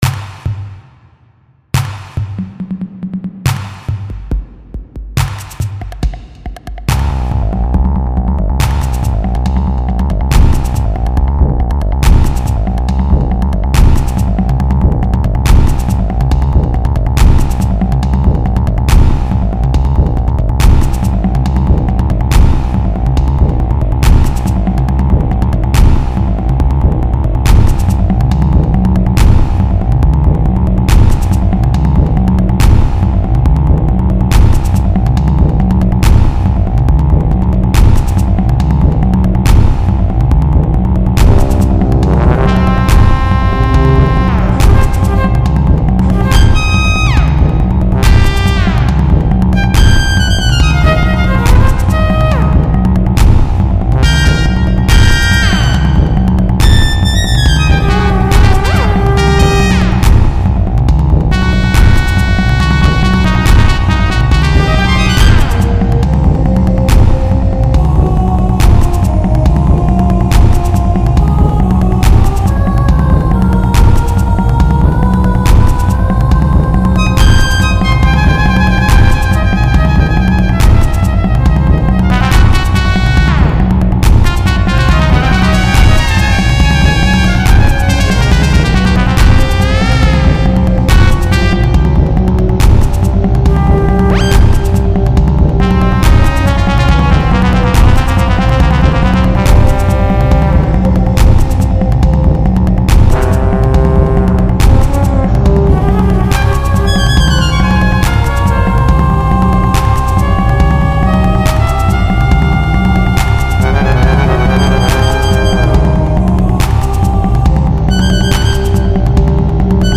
These were all written in music making software.
Experimenting with warrior, tribal music
lots of vibrating harmony